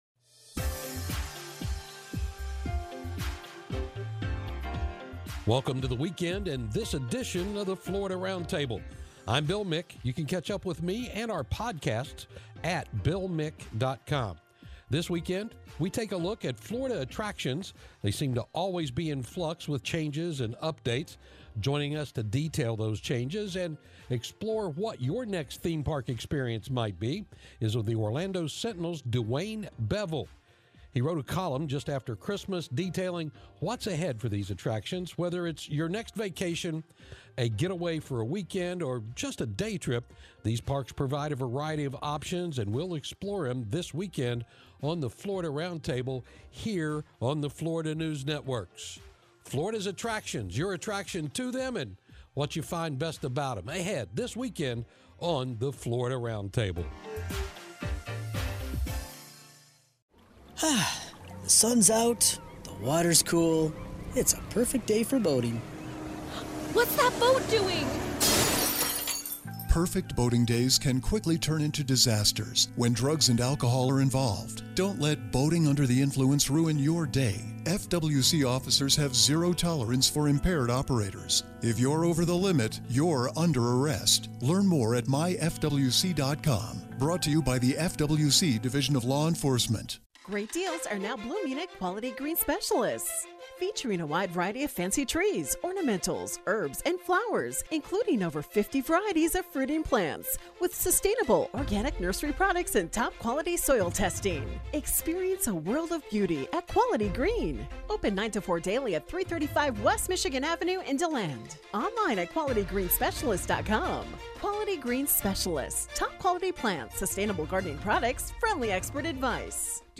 FNN's Florida Roundtable is a weekly, one-hour news and public affairs program that focuses on news and issues of Florida.